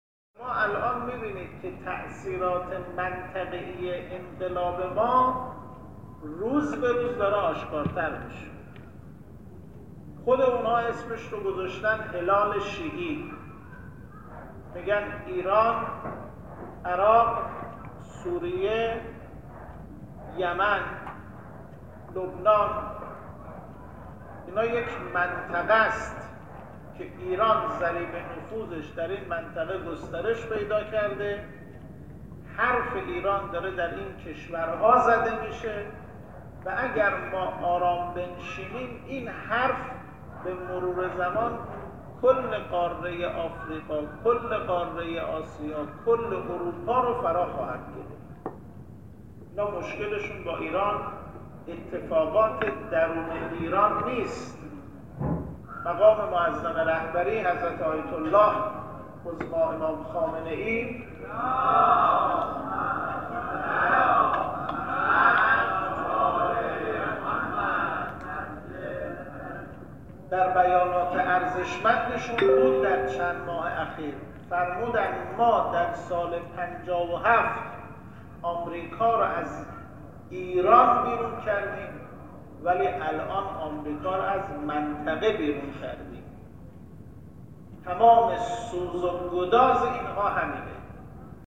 در تفسیر آیات نور که یکشنبه شب در مسجد امام خمینی (ره) برگزار شد